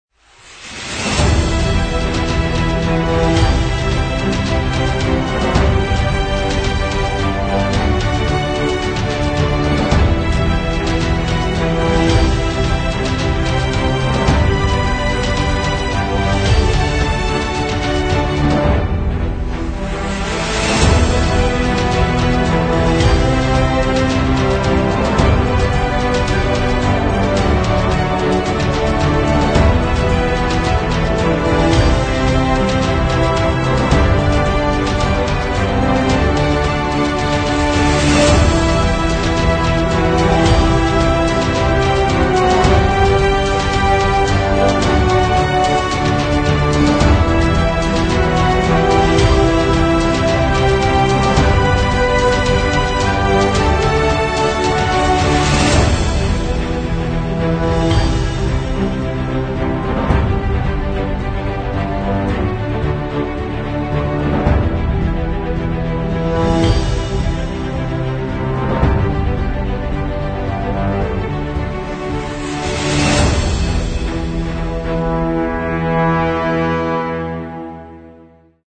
美丽，强大，史诗和鼓舞人心的预告片！